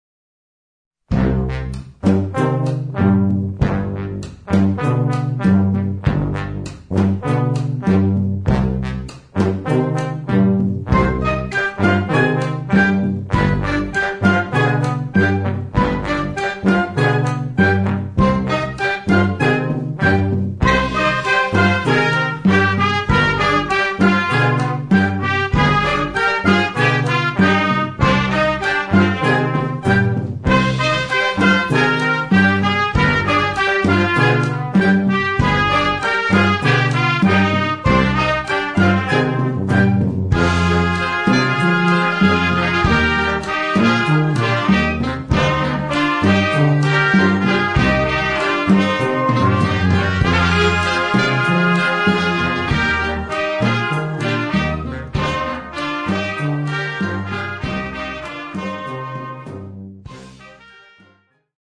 formation d'une dizaine de musiciens
dans un arrangement pour harmonie ou fanfare